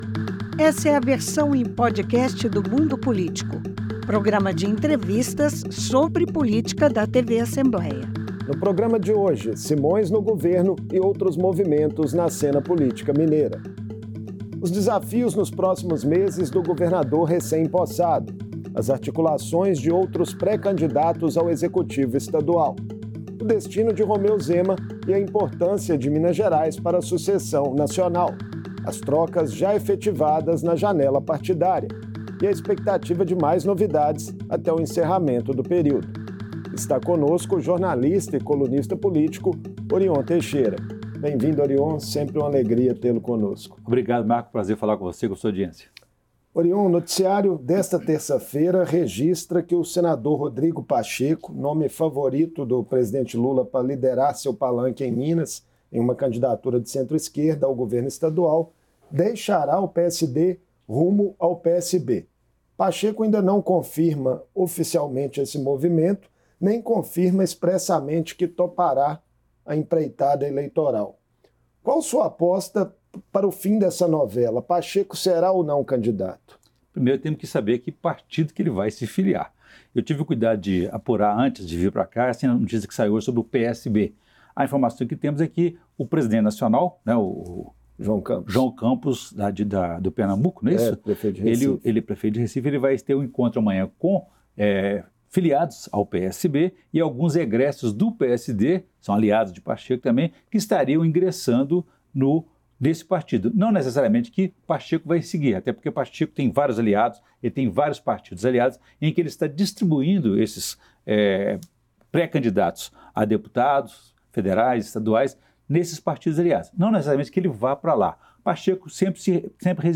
A disputa eleitoral no estado, os possíveis cenários e principais candidatos ao governo foram alguns dos temas abordados na entrevista do jornalista e colunista político